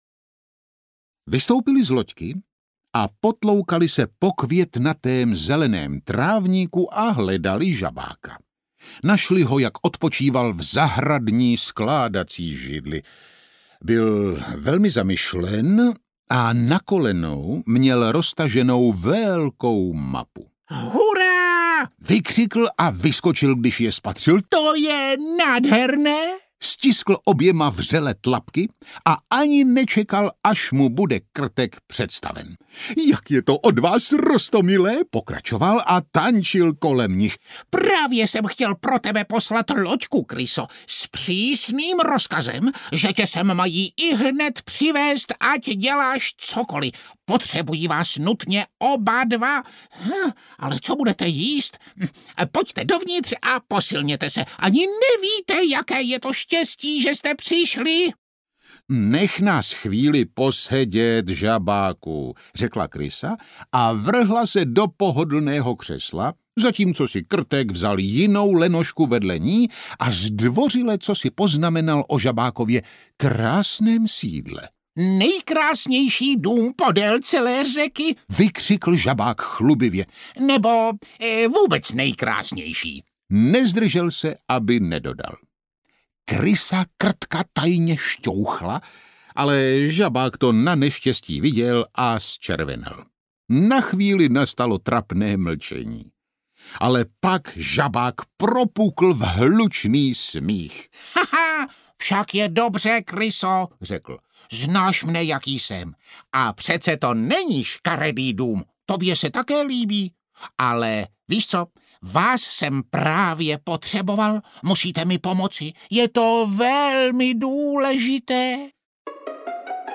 Žabákova dobrodružství audiokniha
Ukázka z knihy
zabakova-dobrodruzstvi-audiokniha